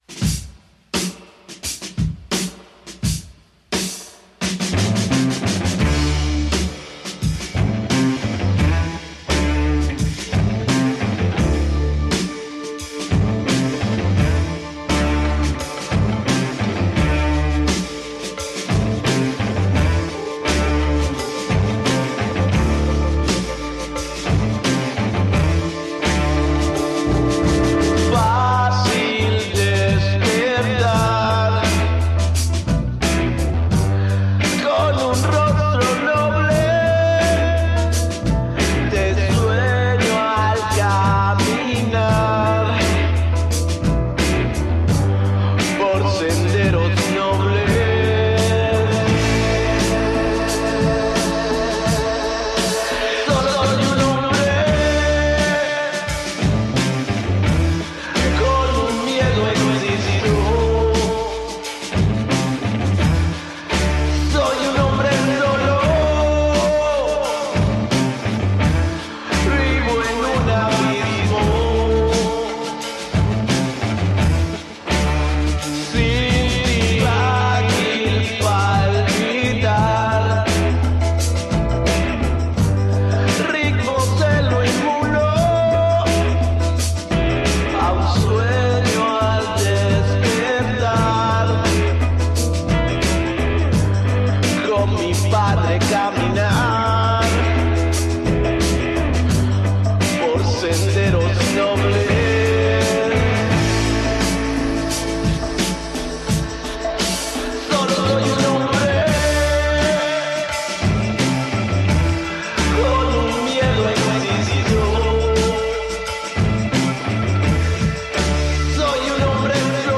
Tags: Psicodelico